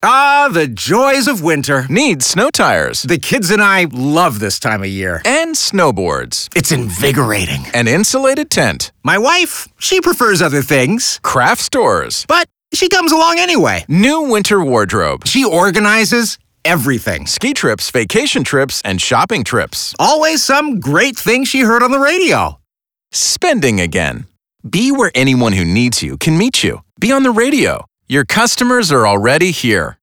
New PSA “Winter” is a reminder to advertisers that radio is here to heat up retail sales year-round.